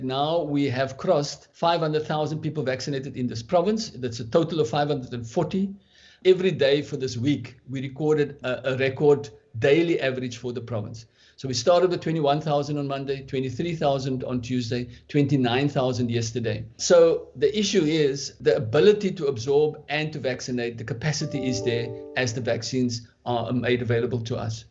Head of Health, Dr. Keith Cloete says the additional clinic would significantly aid in ramping up the province’s vaccination capabilities.